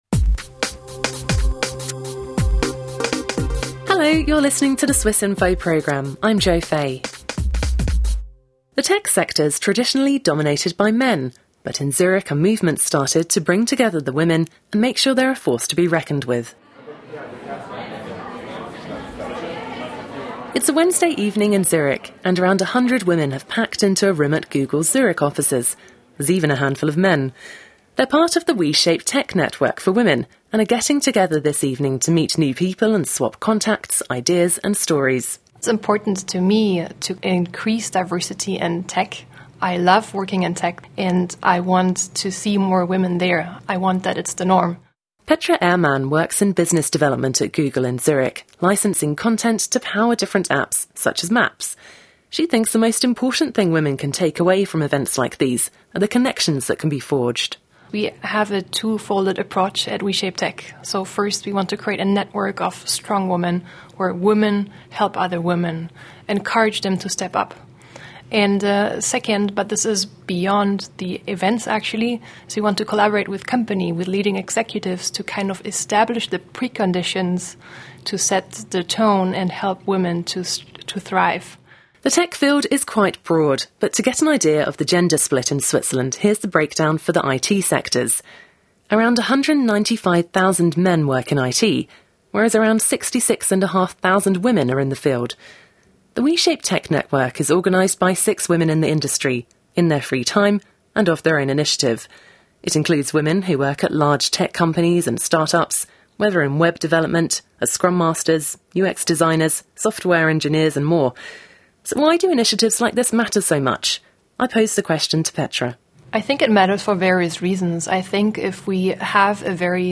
In Zurich, women who work in the tech industry are joining forces to find out about new opportunities and swap contacts and experiences. We join the We Shape Tech Network for an evening at Google Zurich.